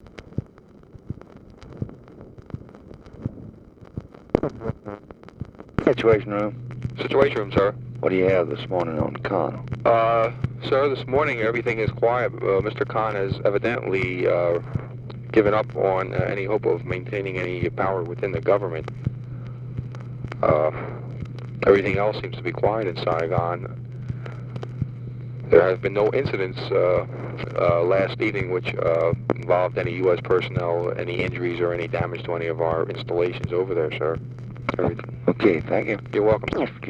Conversation with WH SITUATION ROOM, February 22, 1965
Secret White House Tapes